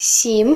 Added Ukrainian numbers voice files